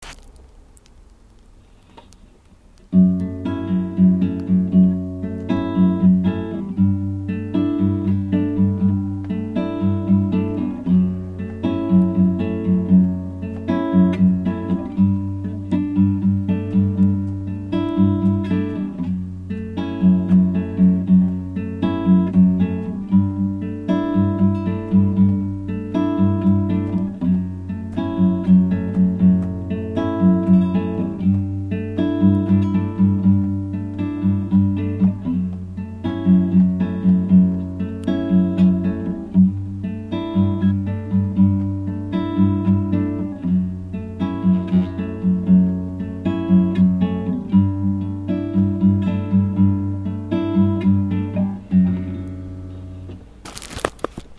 ポップス